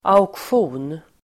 Uttal: [a_oksj'o:n]